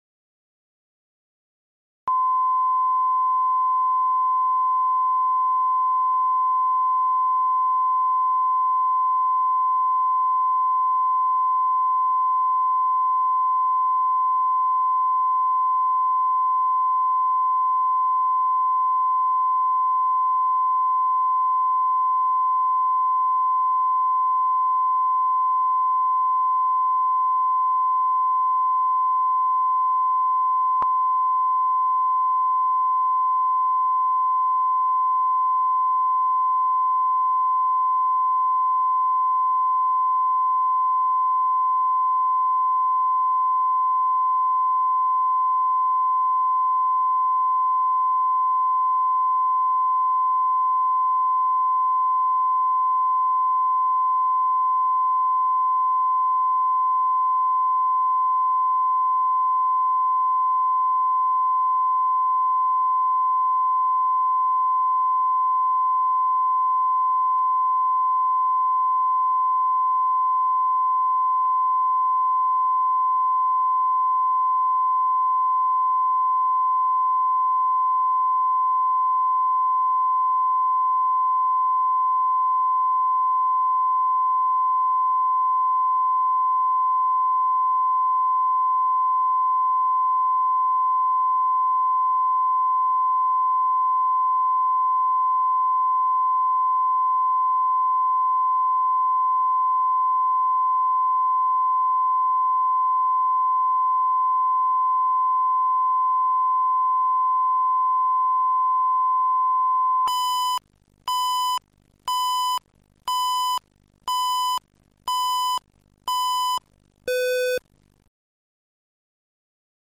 Аудиокнига Букет для любимой